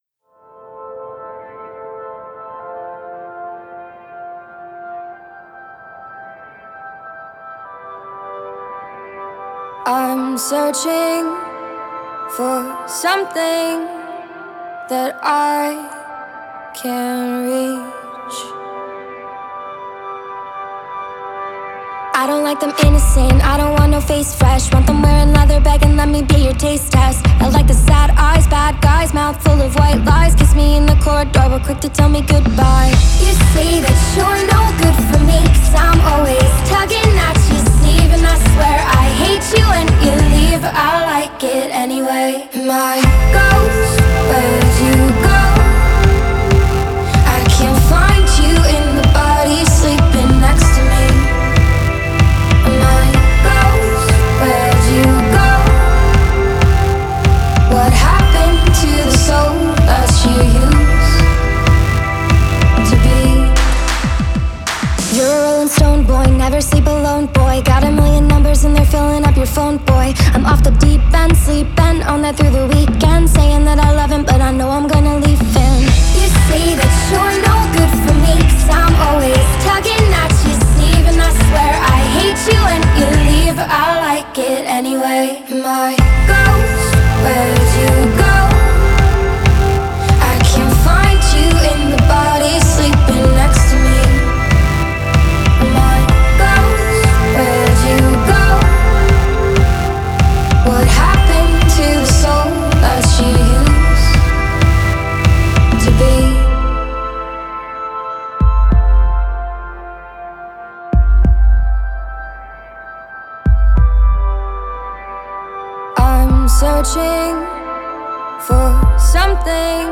is. this song also has a creepy element to it